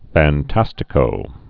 (făn-tăstĭ-kō)